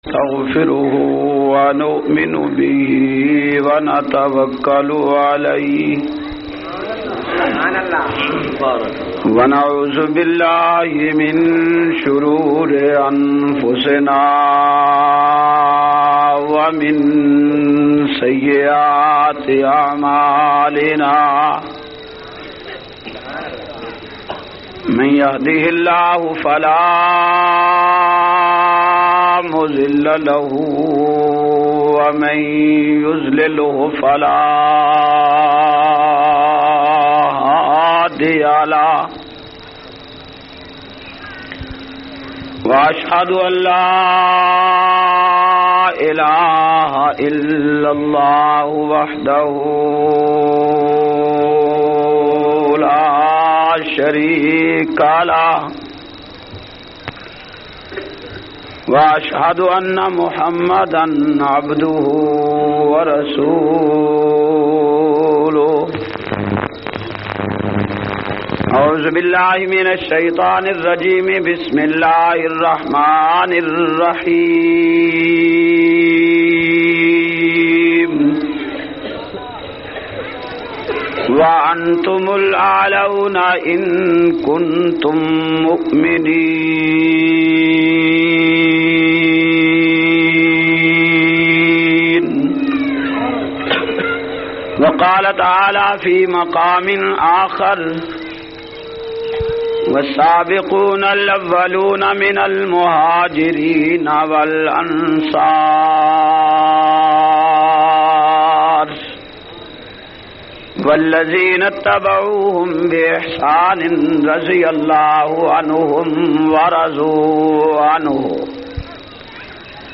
378- Muqadma e Qatal Part 1 Punjabi Bayan.mp3